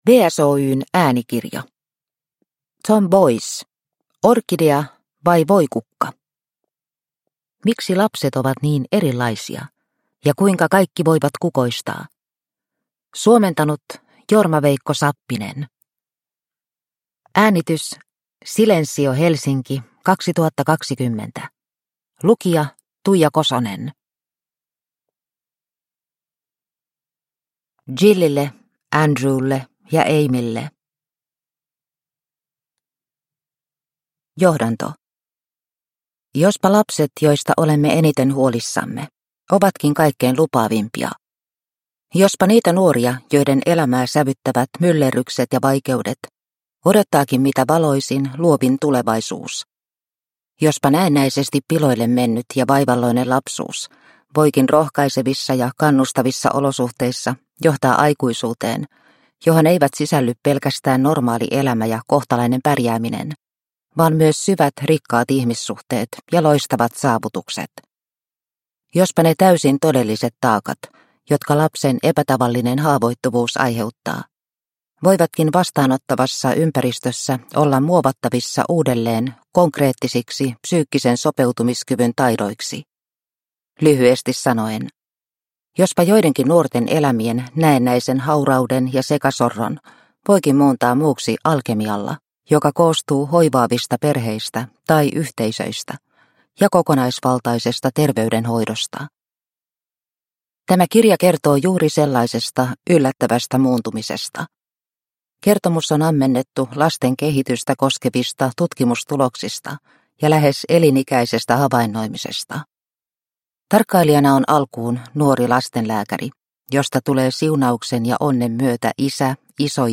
Orkidea vai voikukka? – Ljudbok – Laddas ner